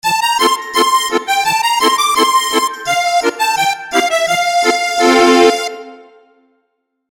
Fanfare (Pirate)